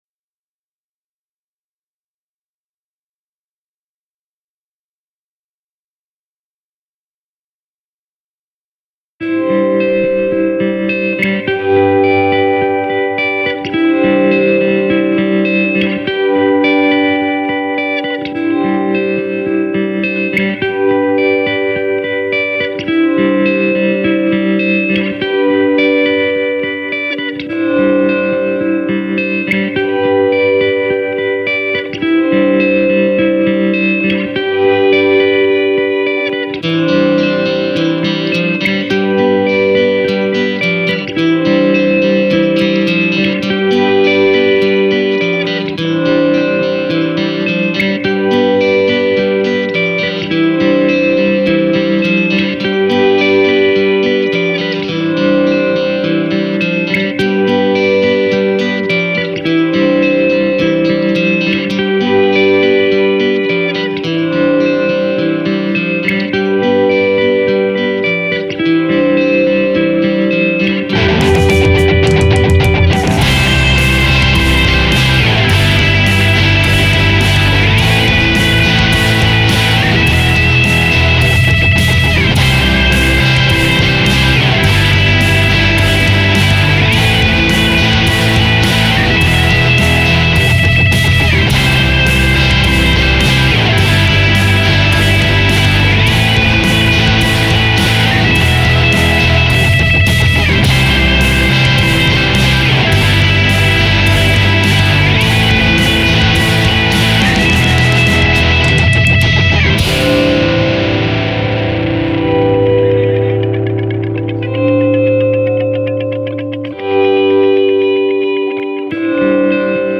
C'est 10 fois trop long ! On se fait vraiment chier à la longue (aussi bien couplet que refrain). Tu pourrais couper ces arpèges.
Je ne vois rien de bien évolué dans ta ligne de basse !
Tu ne fais que répéter deux fois la même chose.